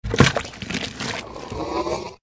TL_quicksand.ogg